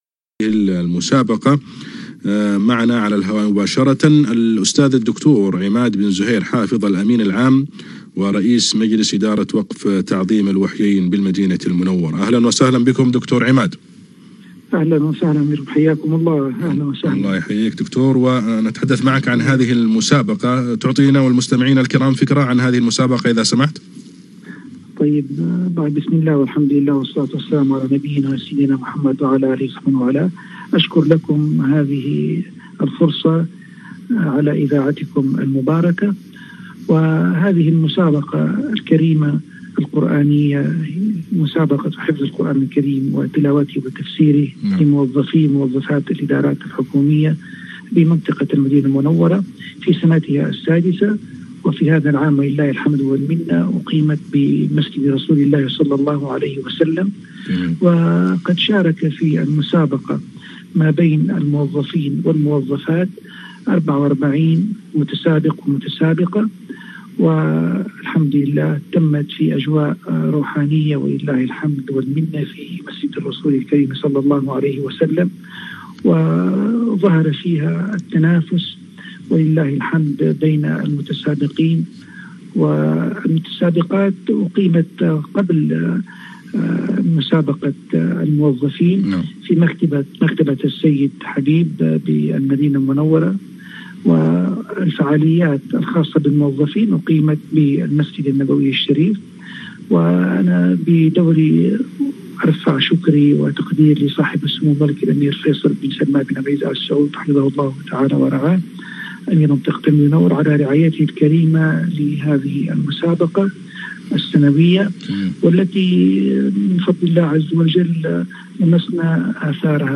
لقاء إذاعي